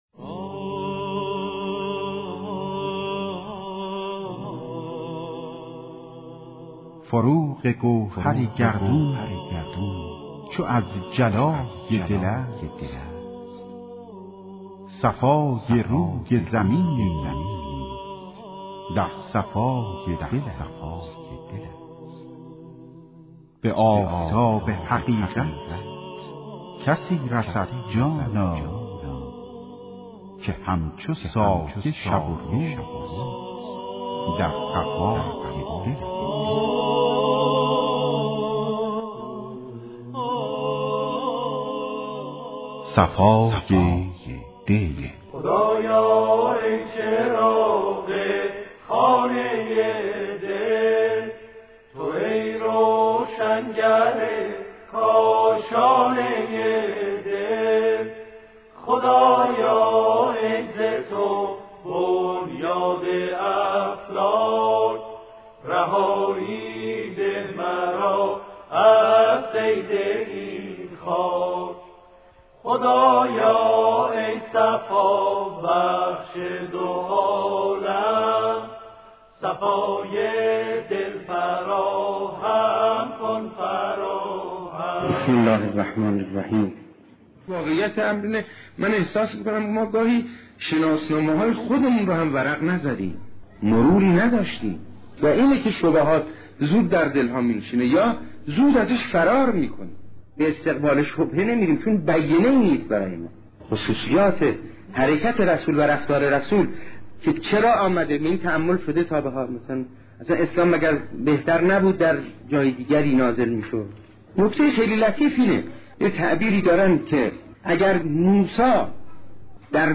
مجموعه سخنرانی